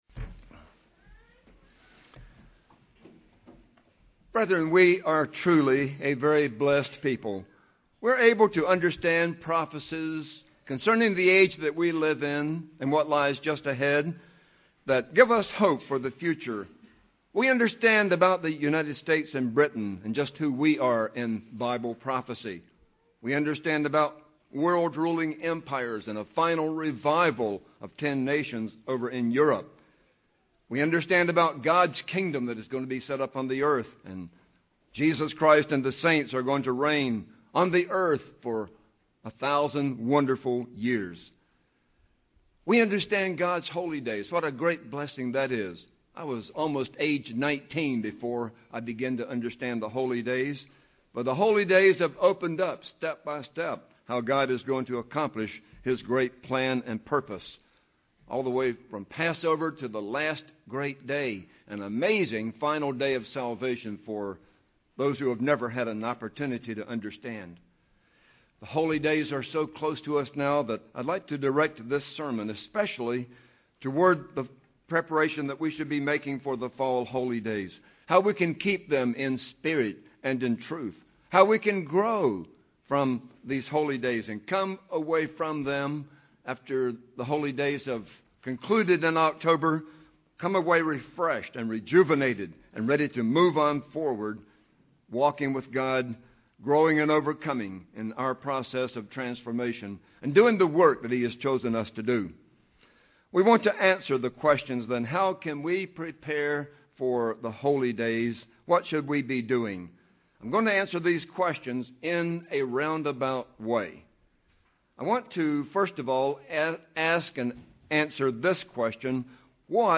Listen to this sermon to find out what preparations we should be making for the Fall Holy Days so we can be refreshed and walk with God during the year ahead.